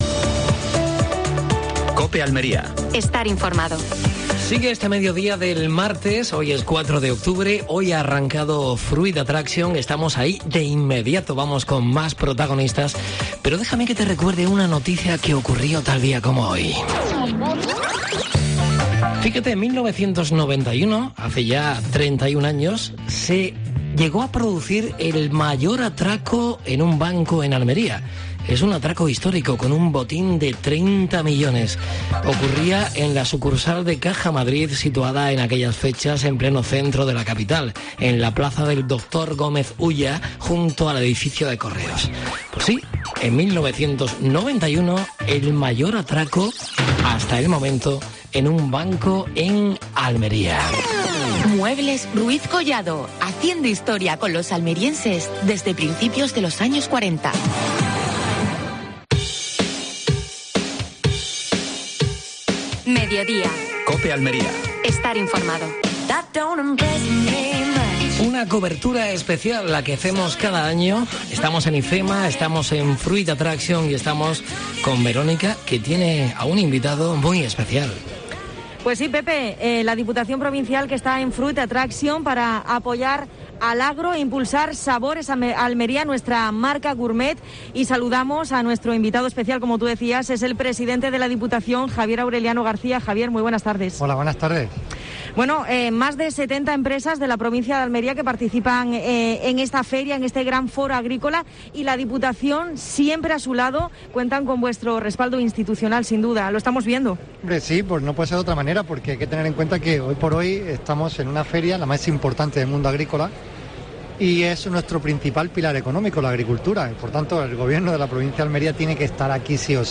AUDIO: Especial desde Fruit Attraction (IFEMA). Entrevista a Javier A. García (presidente Diputación de Almería).